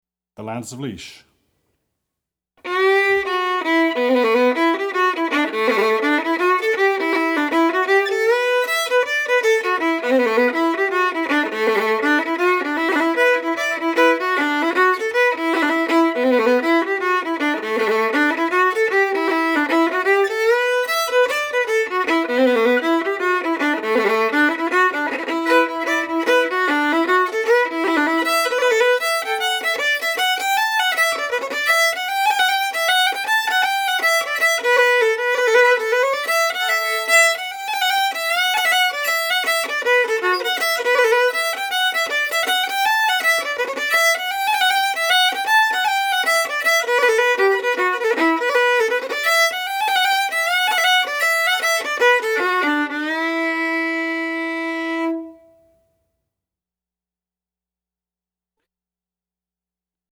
DIGITAL SHEET MUSIC - FIDDLE SOLO
Fiddle Solo, Celtic/Irish, Reel